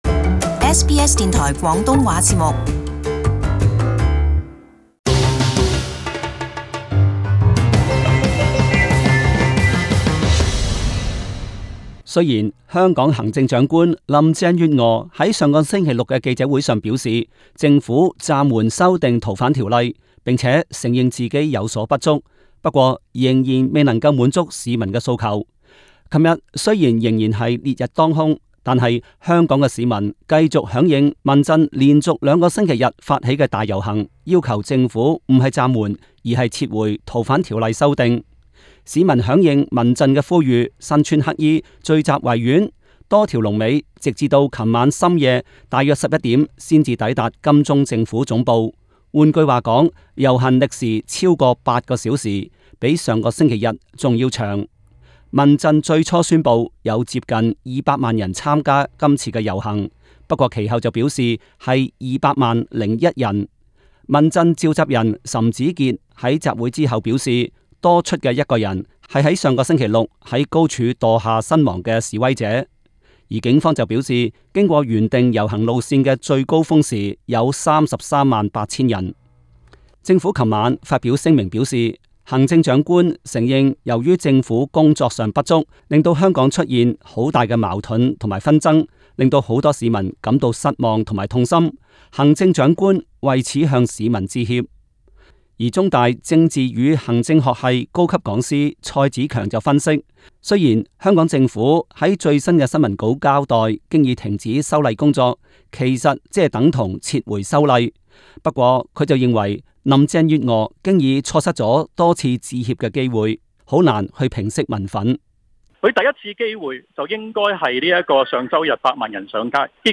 Source: AAP SBS广东话播客 View Podcast Series Follow and Subscribe Apple Podcasts YouTube Spotify Download (14.7MB) Download the SBS Audio app Available on iOS and Android 《逃犯条例》修订的风波继续在香港发酵。